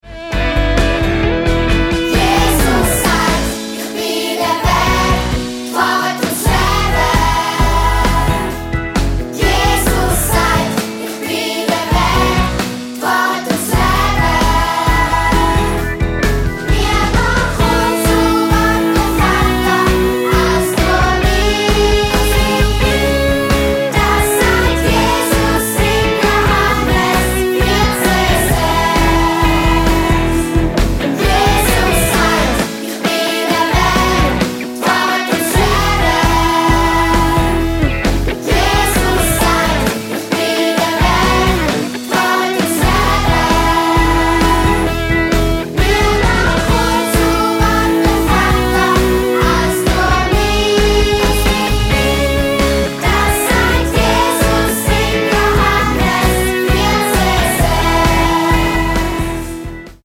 20 Bibelverssongs
24 Bibelverse peppig und eingängig vertont